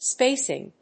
音節spác・ing 発音記号・読み方
/ˈspesɪŋ(米国英語), ˈspeɪsɪŋ(英国英語)/